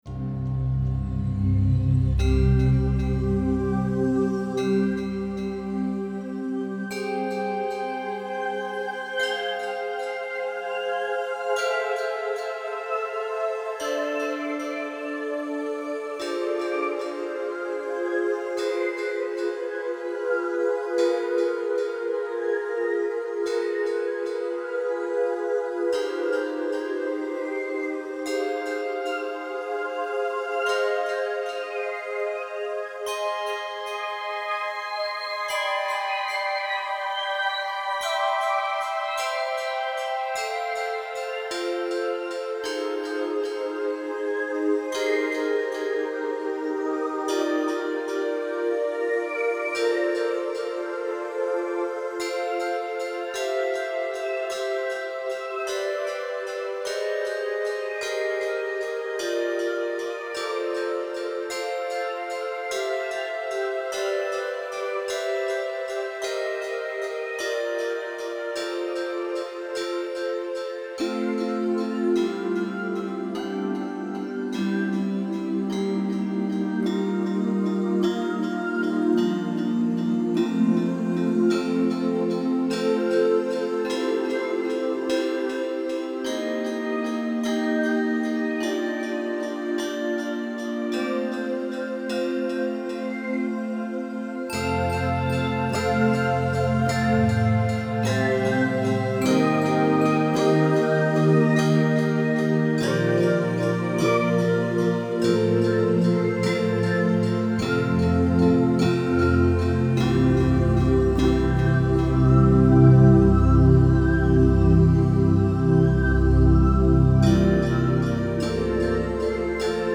Synthesizer Improvisation
at home, Sunday 9/4/11